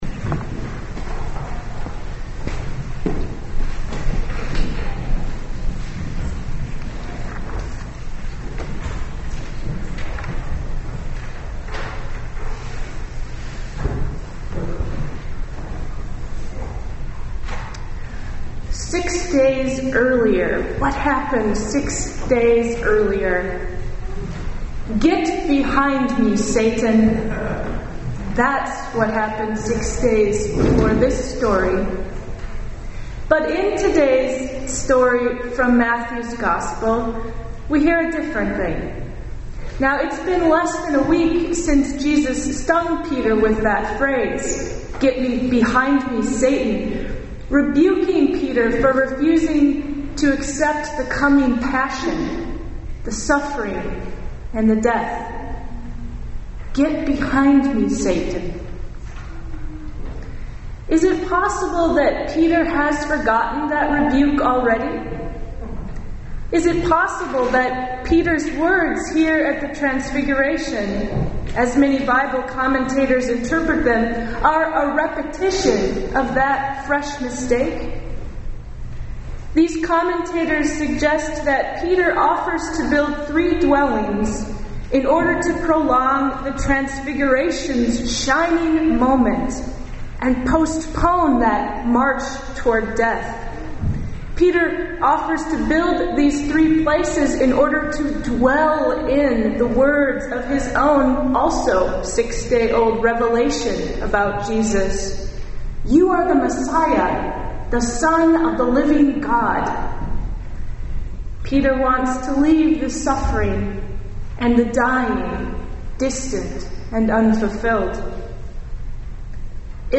2010-11 Sermons Online